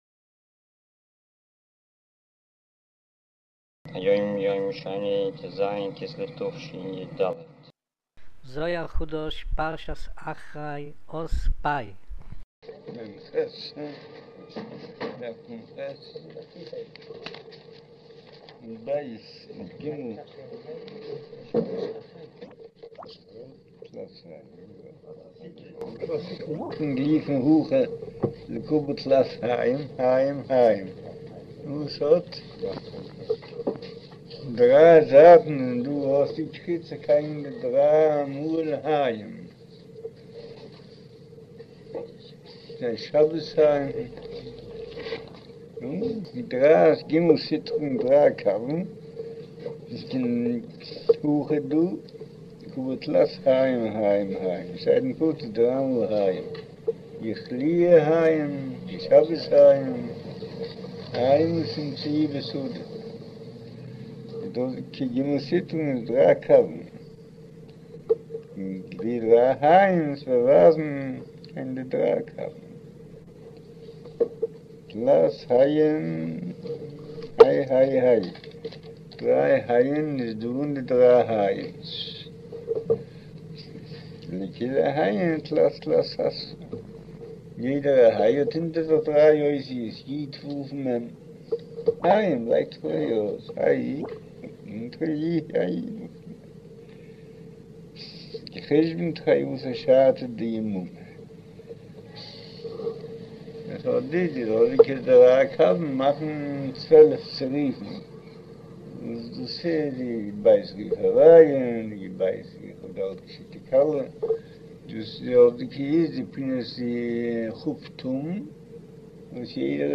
אודיו - שיעור